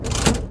lever4.wav